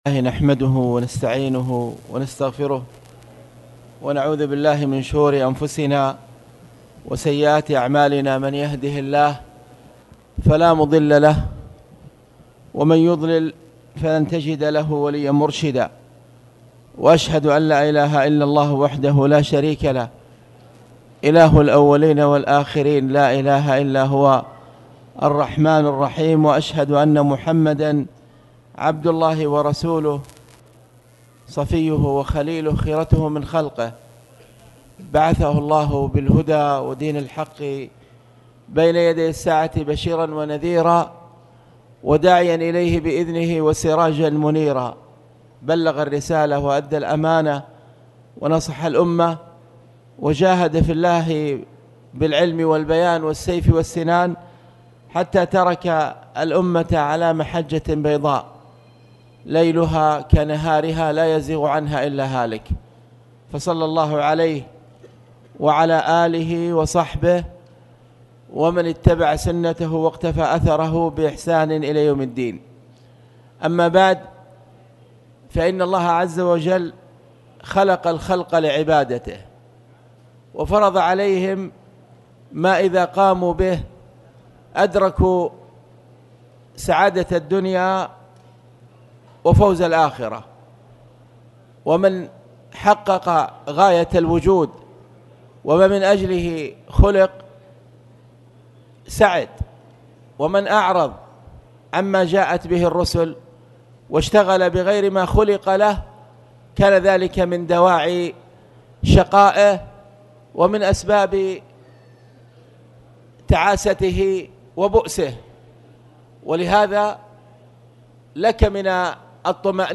تاريخ النشر ٧ محرم ١٤٣٨ هـ المكان: المسجد الحرام الشيخ